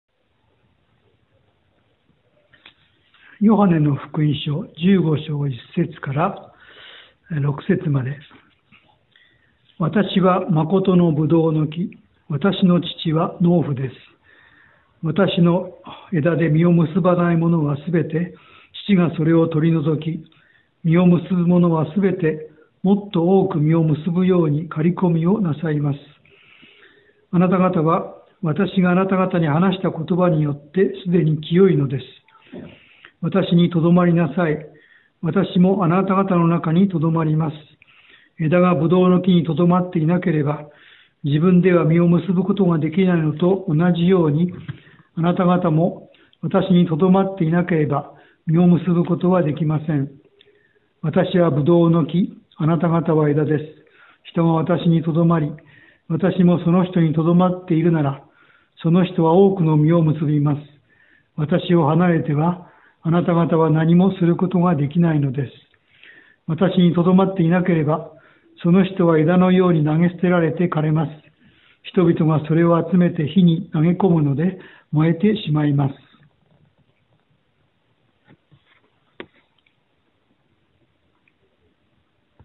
BibleReading_John15.1-6.mp3